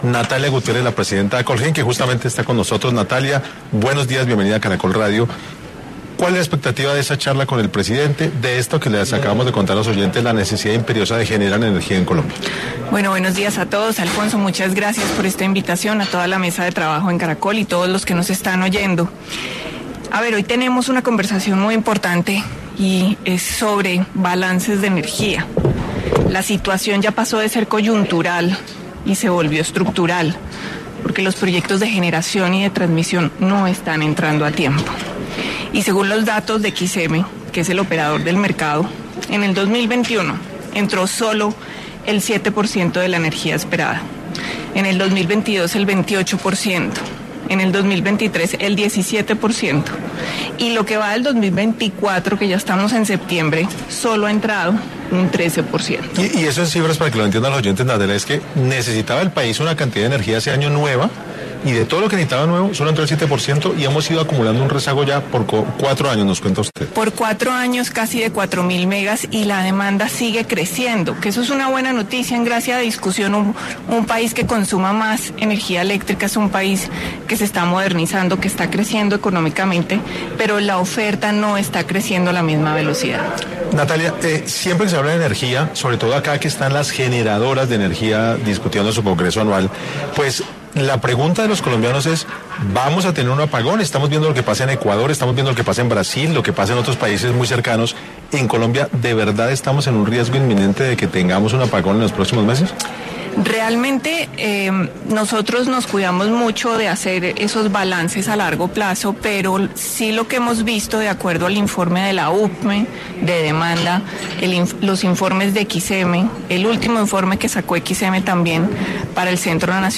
En 6AM de Caracol Radio estuvo